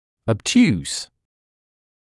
[əb’tjuːs][эб’тьюːс]тупой; тупоконечный (об углах)